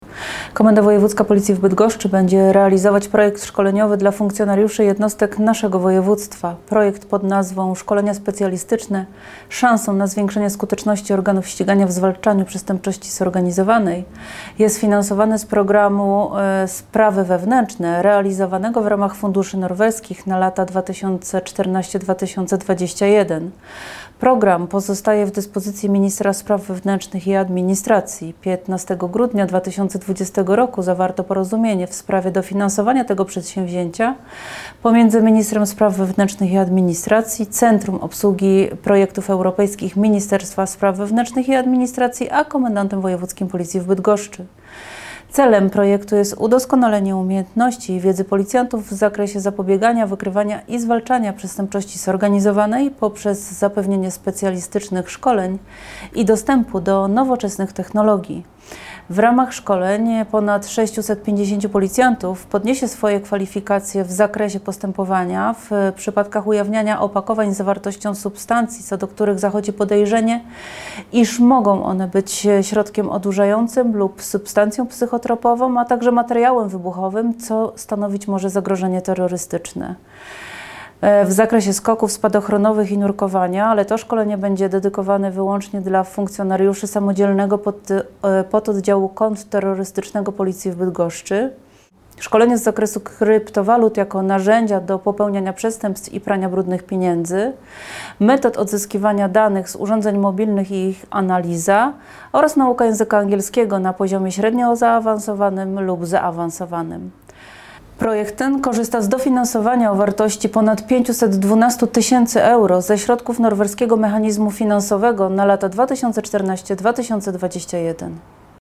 Filmowi towarzyszy muzyka.